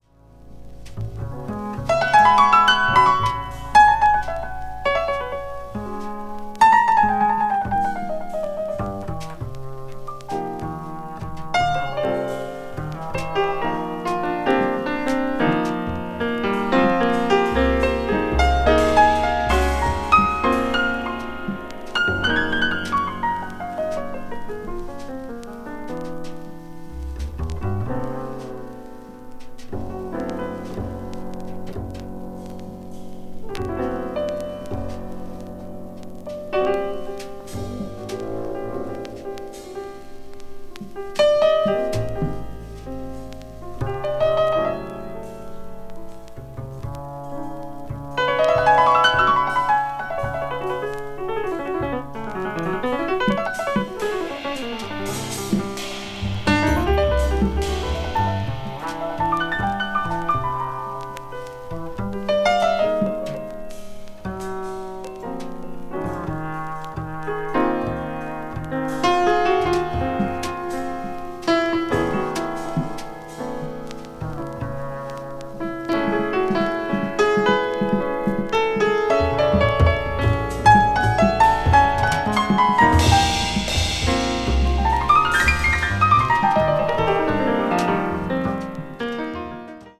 avant-jazz   contemporary jazz   ethnic jazz   free jazz